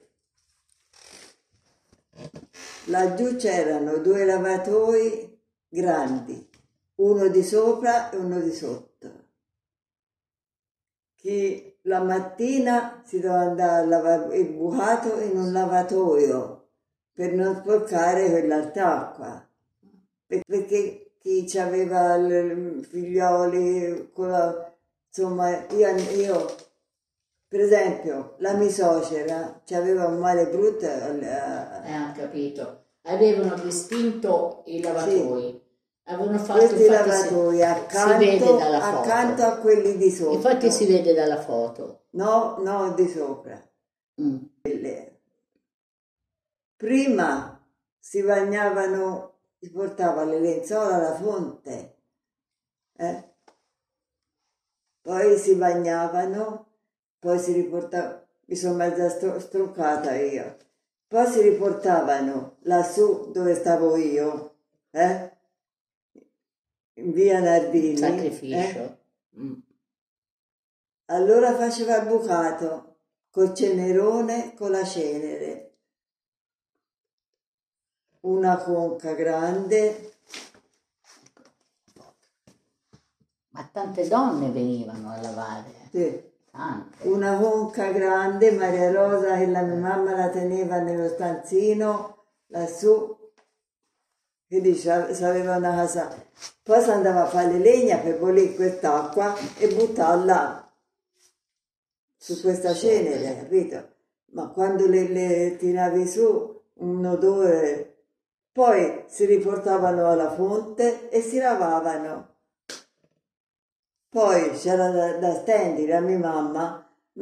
Testimonianza audio